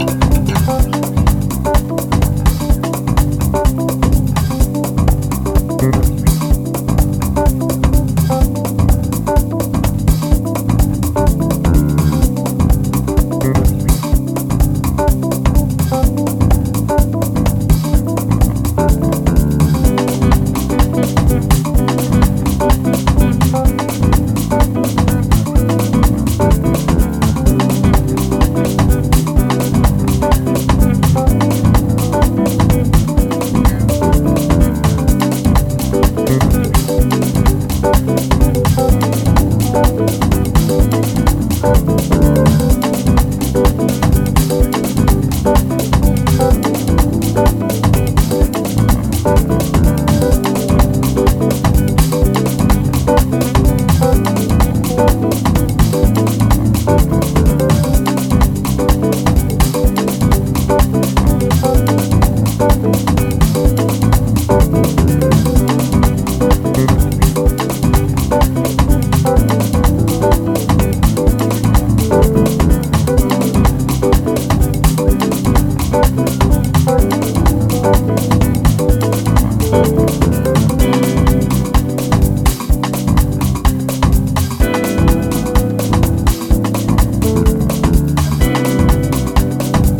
ジャンル(スタイル) DEEP HOUSE / NU DISCO